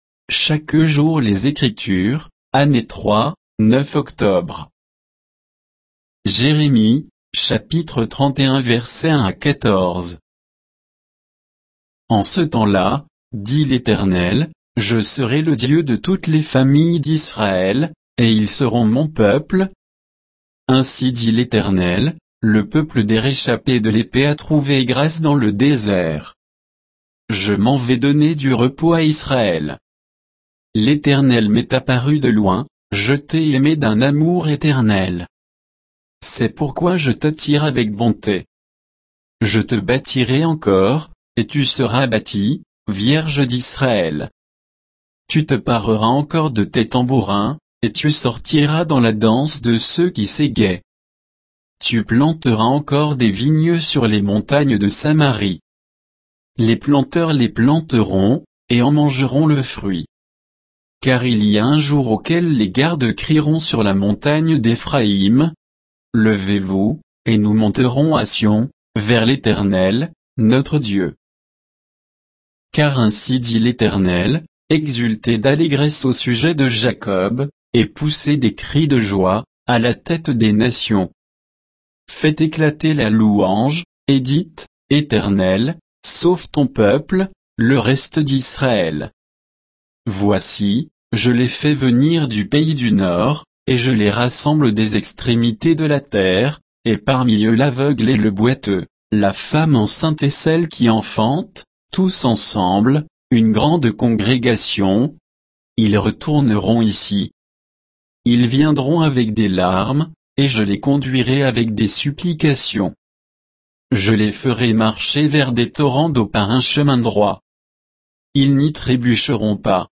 Méditation quoditienne de Chaque jour les Écritures sur Jérémie 31